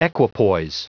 Prononciation du mot equipoise en anglais (fichier audio)